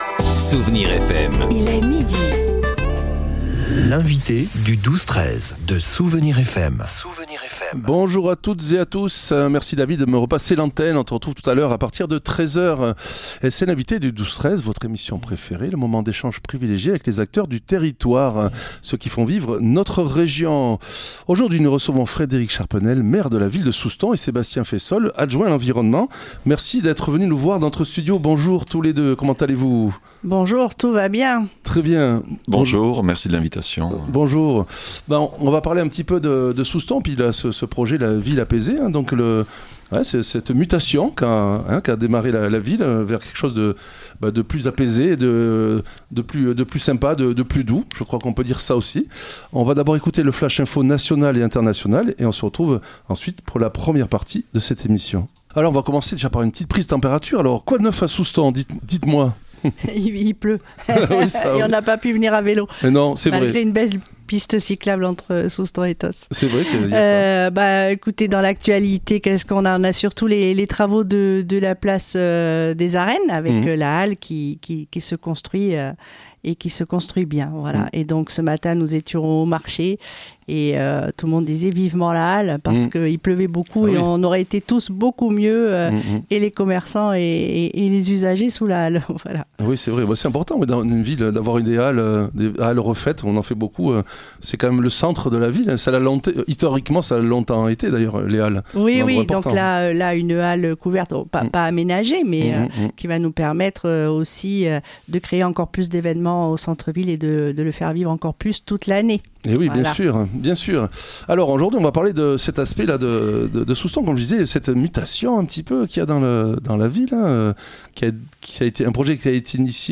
Nous recevions aujourd'hui Madame Frédérique Charpenel, Maire de Soustons et Sébastien Faissolle, adjoint à l'environnement.